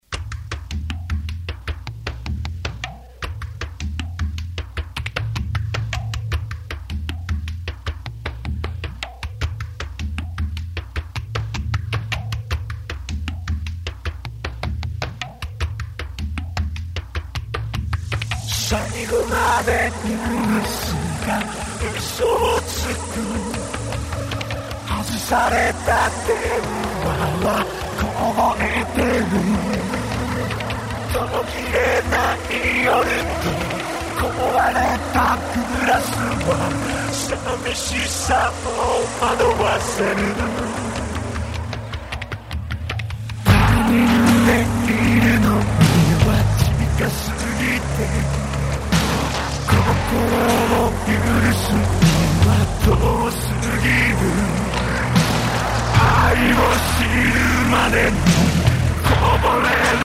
続いてカセットを装着して再生テスト．
結果：ワウフラ炸裂でとても音楽ではない → 失敗